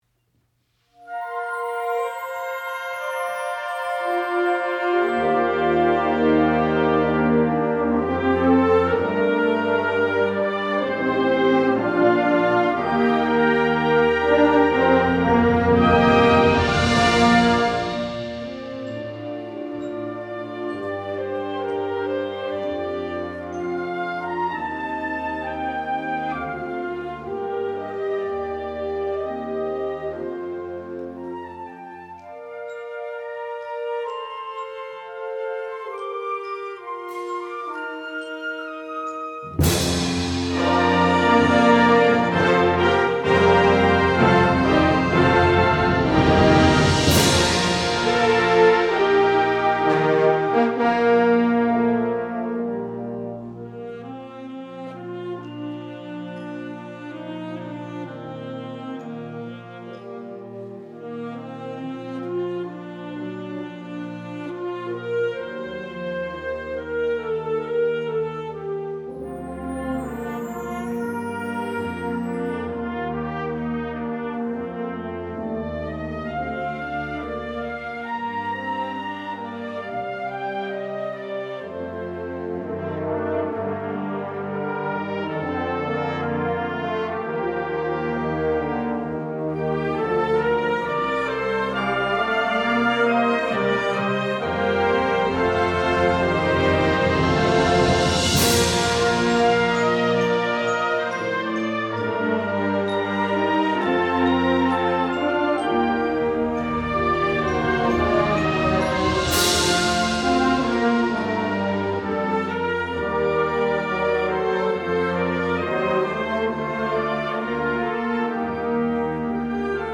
歌心あふれる親しみやすいメロディーと豊かな響きは、まさに市制100周年の節目を飾るにふさわしいものです。
・世界初演演奏
大社・高須・鳴尾・上ケ原・苦楽園・深津各中学校吹奏楽部
市立西宮・関西学院・報徳学園各高等学校吹奏楽部
（2026年2月1日、西宮市民会館アミティ・ベイコムホール）